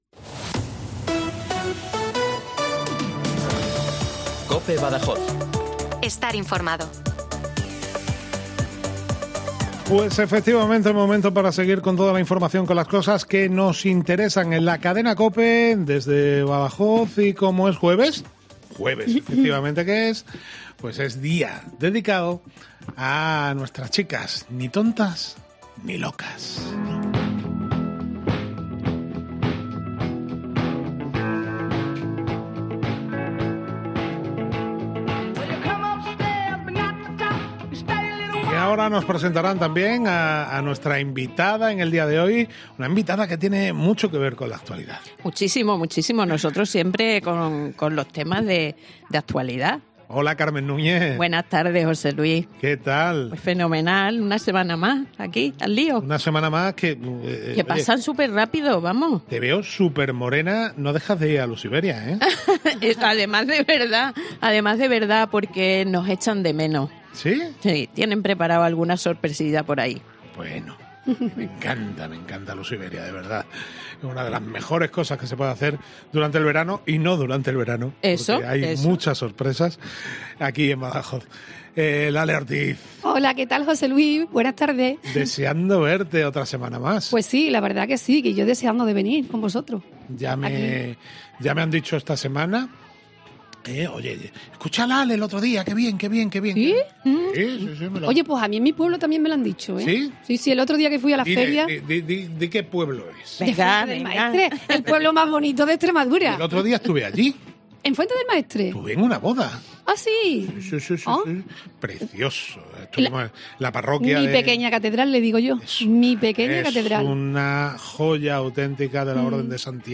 Hoy, en nuestro programa de Mediodía COPE "Ni Tontas Ni Locas", hemos tenido una entretenida charla